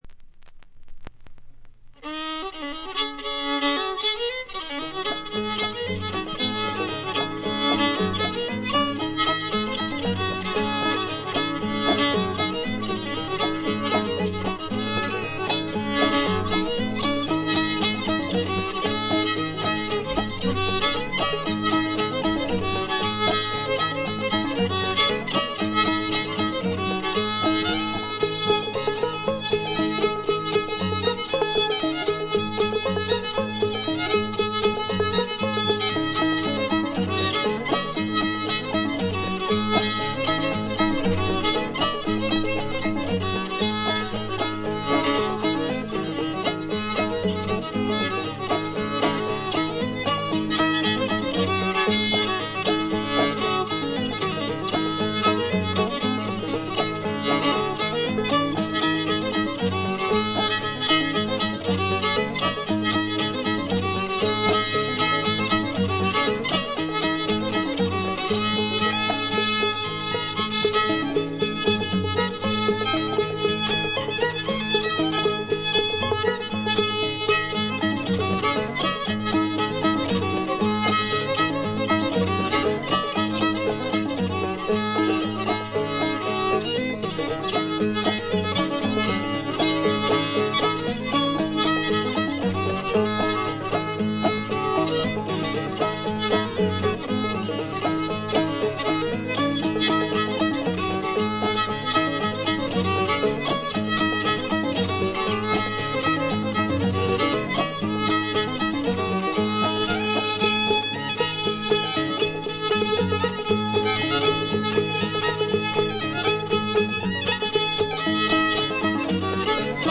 アパラチアの伝承音楽の継承者として注目される存在でした。
試聴曲は現品からの取り込み音源です。
Banjo, Mandolin, Fiddle
Dulcimer
Guitar
Recorded At - Radio WDBS Studios, Durham